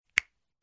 HUMAN-SNAP-FINGERS.mp3